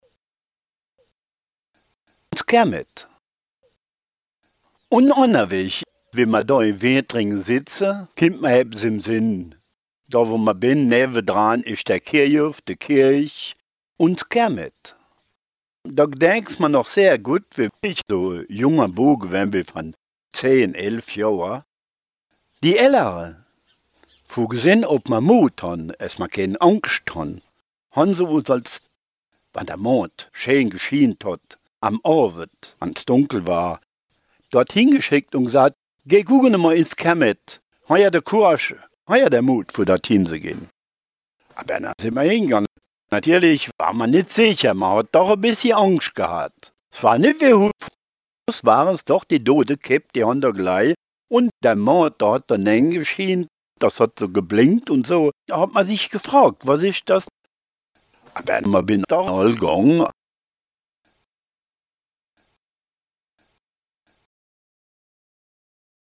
Contes et récits en ditsch enregistrés dans les communes de Racrange, Vallerange, Bérig-Vintrange, Harprich, Eincheville, Viller-Béning, Viller, Viller-Boustroff et de Boustroff.